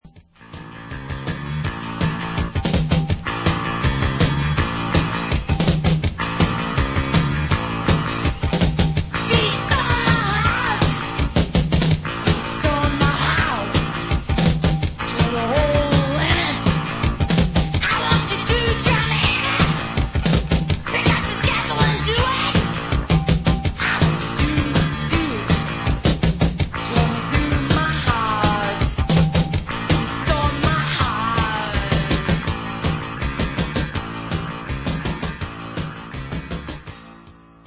chant
guitare
basse